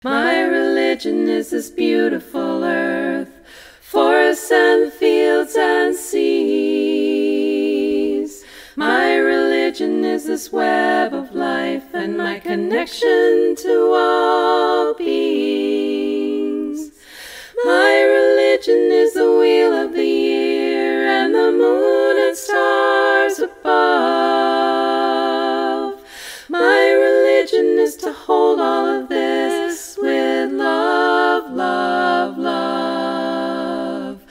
A cappella
SATB